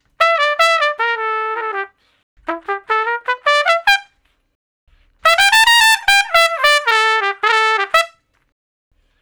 084 Trump Shuffle (E) 01.wav